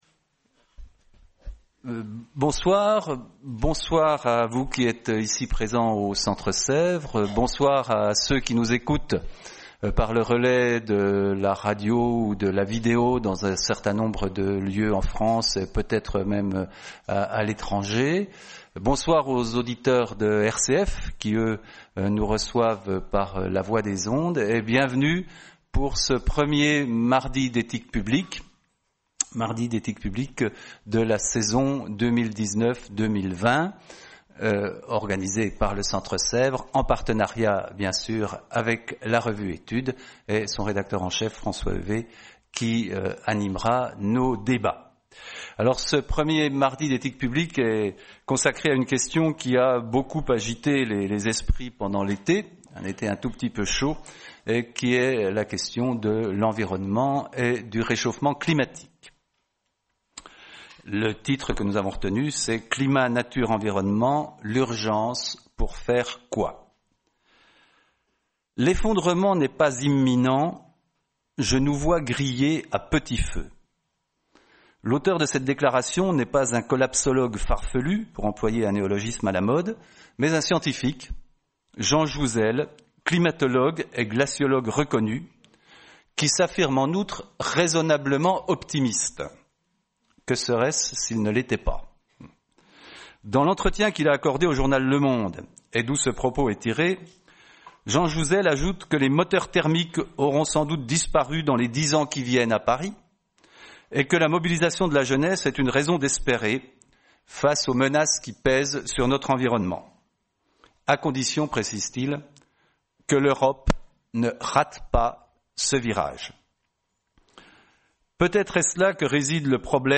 Soirée Mardi d’éthique publique du 1er octobre 2019 : Climat, nature, environnement. L’urgence pour faire quoi ?